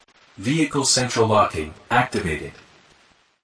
Tesla Lock Sound English American Man
English male voice saying
with an american accent
(This is a lofi preview version. The downloadable version will be in full quality)
JM_Tesla-Lock_English-US_Man_Watermark.mp3